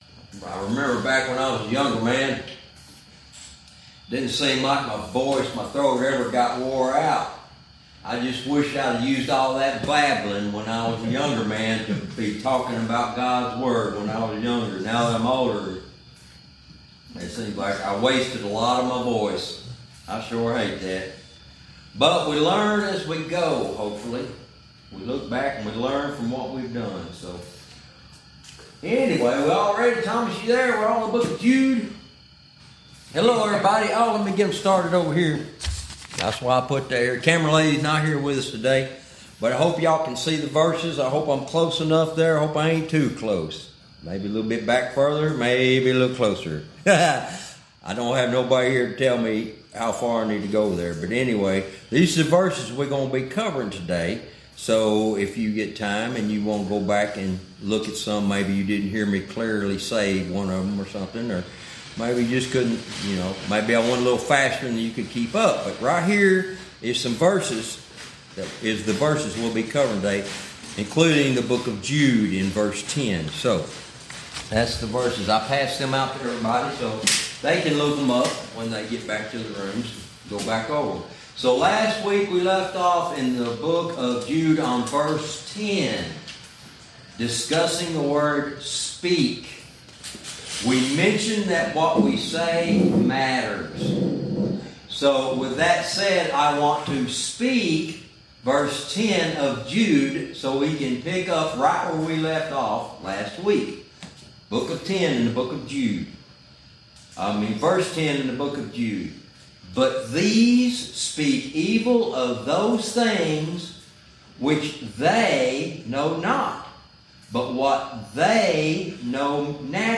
Verse by verse teaching - Lesson 34